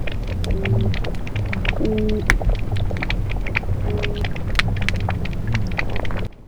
Sound of Oyster Toadfish.
(Click to see larger image) OYSTER TOADFISH AUDIO I OYSTER TOADFISH AUDIO II Recorded April, 2009 - Edisto Island, South Carolina The Oyster Toadfish is a bottom dweller, inhabiting shallow waters such as the lagoon at Edisto. The fog-horn call is produced by the male when courting females at the nest site. Both sexes make grunting and growling sounds when annoyed or frightened.
weboystertoadfishtwo.wav